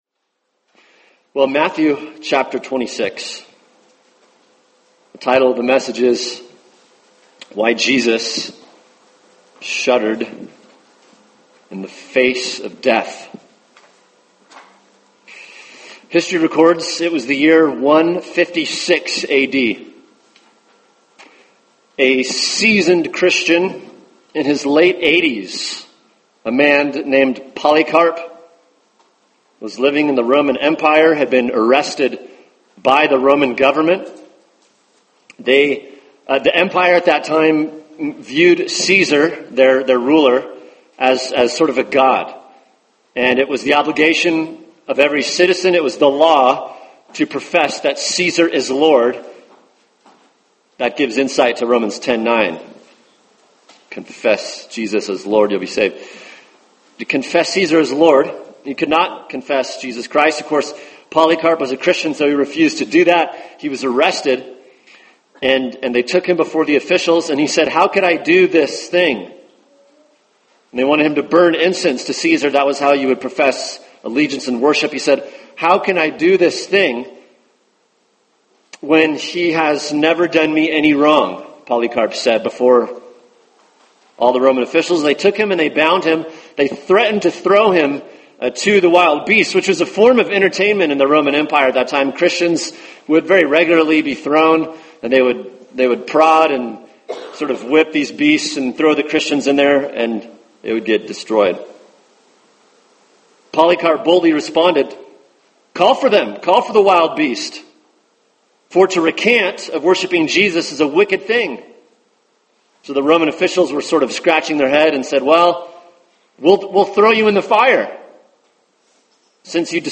[sermon] Matthew 26:36-46 – Why Jesus Shuddered in the Face of Death | Cornerstone Church - Jackson Hole